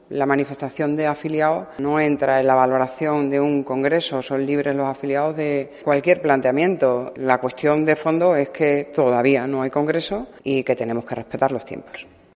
Cristina Teniente ha realizado estas declaraciones a preguntas de los medios este martes en la rueda de prensa posterior a la reunión de la Junta de Portavoces que ha ordenado el próximo pleno en la Asamblea de Extremadura.